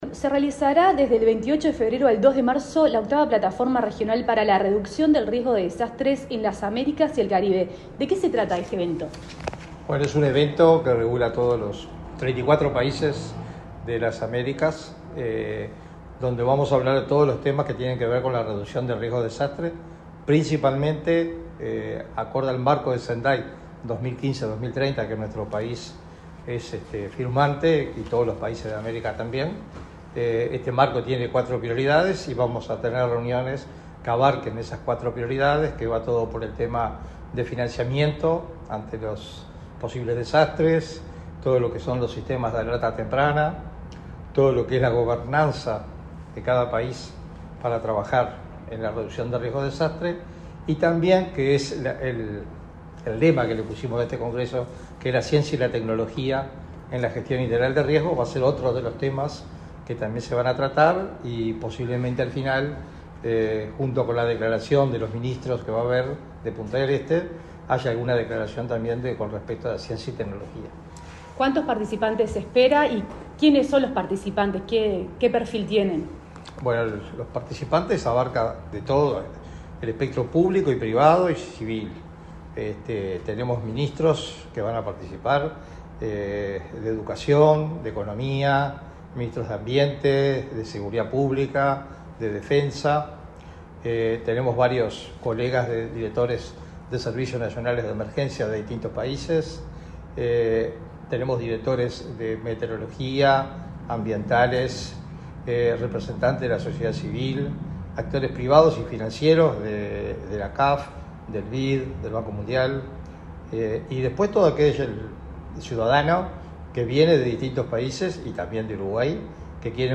Entrevista al director del Sinae, Sergio Rico